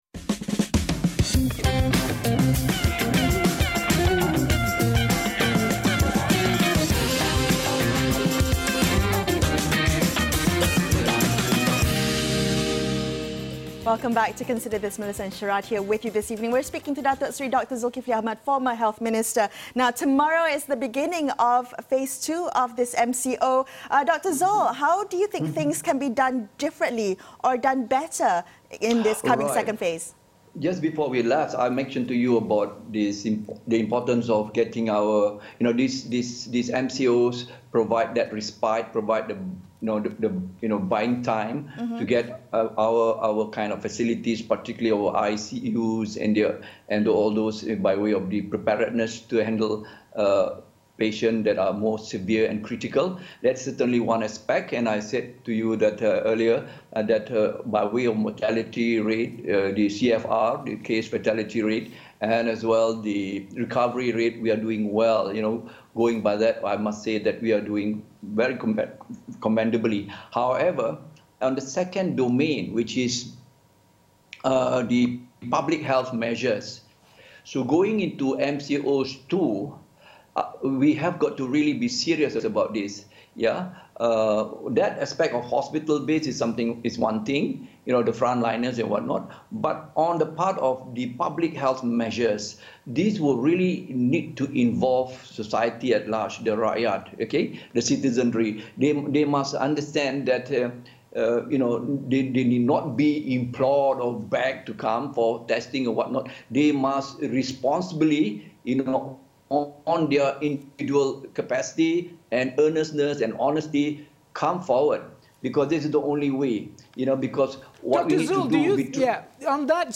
speak to Dr Dzulkefly Ahmad, former Health Minister and Head of Selangor's Special Taskforce on COVID-19.